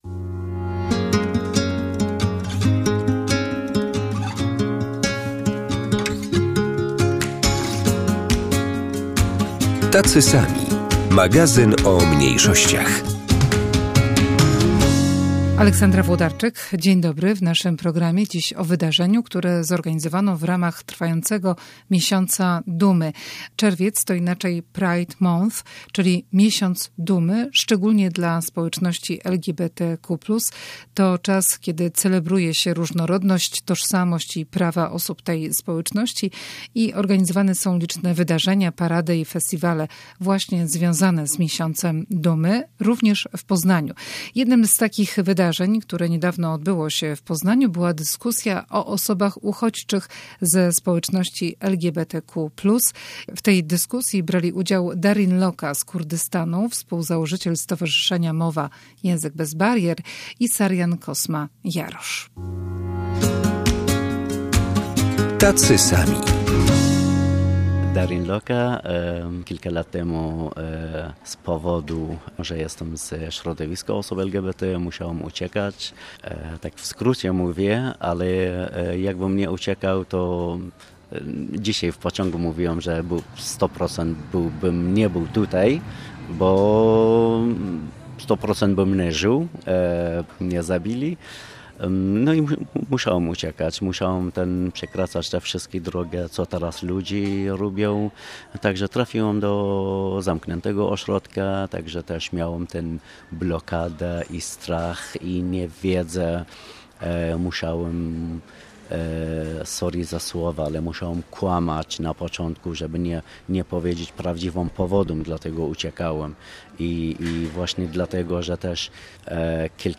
Czerwiec to miesiąc dumy, szczególnie dla społeczności LGBTQ+. Wystawy, koncerty, wydarzenia i dyskusje odbywają się w Poznaniu - z tej okazji w naszym magazynie o mniejszościach "Tacy sami" rozmowa o sytuacji osób uchodźczych LGBTQ+.